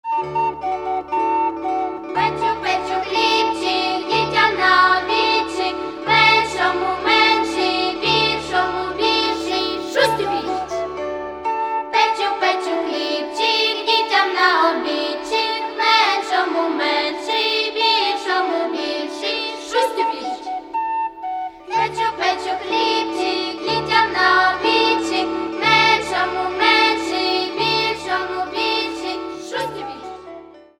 Каталог -> Народная -> Акапельное пение и хоры